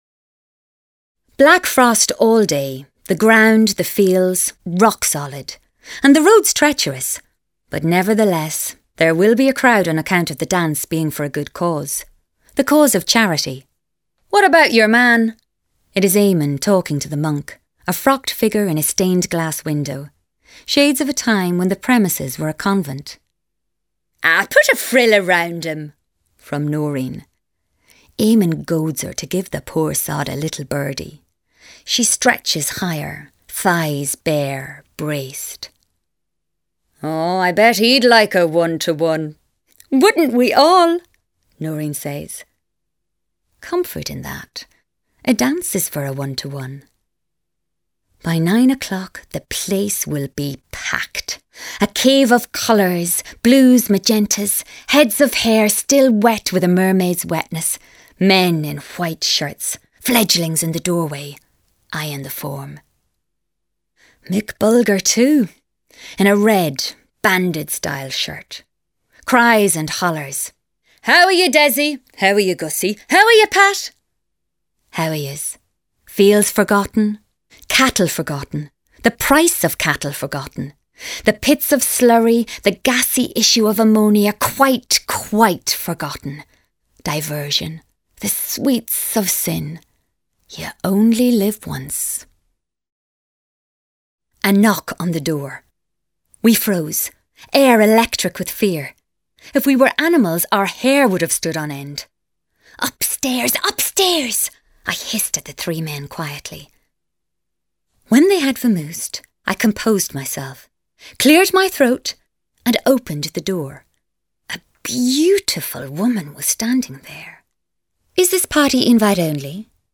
Playing age: 30 - 40s, 40 - 50sNative Accent: IrishOther Accents: Irish
• Native Accent: Irish